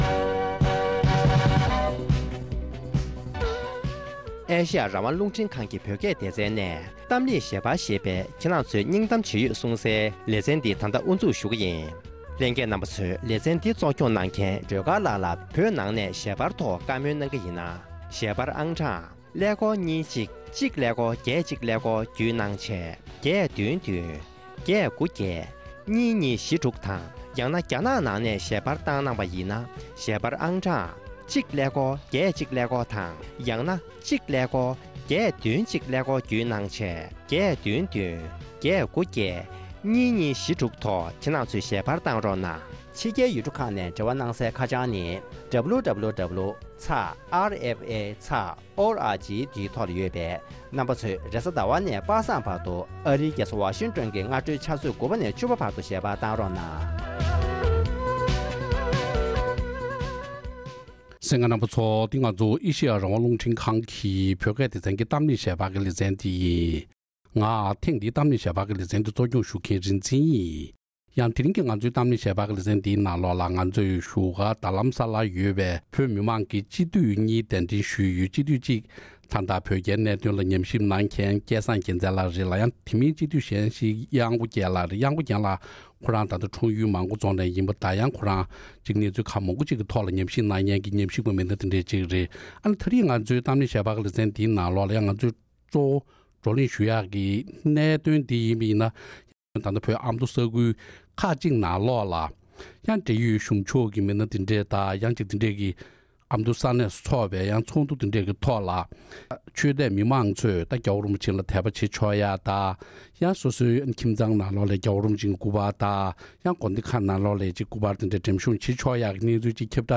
༄༅༎ཐེངས་འདིའི་གཏམ་གླེང་ཞལ་པར་ཞེས་པའི་ལེ་ཚན་ནང་དུ། སྤྱི་ནོེར་༧གོང་ས་༸སྐྱབས་མགོན་ཆེན་པོ་མཆོག་གིས་ནིའུ་ཛི་ལན་དང་ཨོ་སི་ཁྲོ་ལི་ཡའི་ནང་ཟླ་ཕྱེད་ཙམ་གྱི་རིང་རླབས་ཆེན་གྱི་མཛད་འཕྲིན་བསྐྱངས་གནང་ཡོད་པའི་འབྲེལ་ཡོད་གནས་ཚུལ་སྐོར།